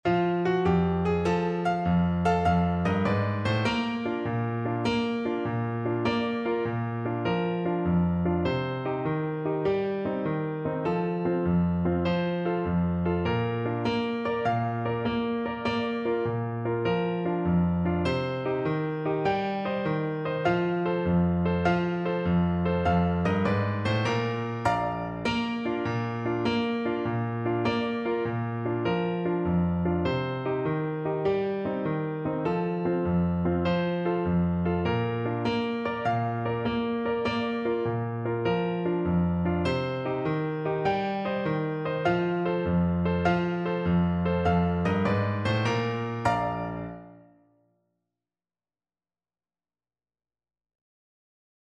Play (or use space bar on your keyboard) Pause Music Playalong - Piano Accompaniment Playalong Band Accompaniment not yet available transpose reset tempo print settings full screen
6/8 (View more 6/8 Music)
Bb major (Sounding Pitch) C major (Clarinet in Bb) (View more Bb major Music for Clarinet )
Allegro .=c.100 (View more music marked Allegro)